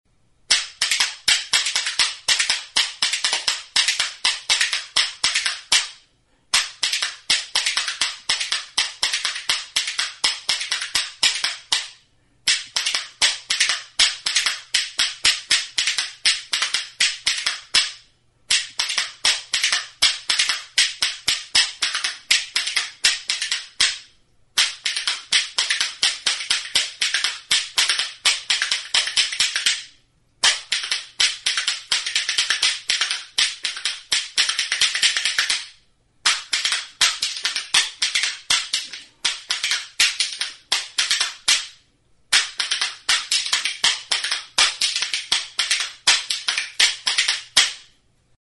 Instrumentos de músicaCUCHARAS
Idiófonos -> Golpeados -> Indirectamente
Grabado con este instrumento.
Eskuz egindako ezpelezko bi goilare arrunt dira; kirtena luzea dute, eta zalia sasi borobila.
MADERA; BOJ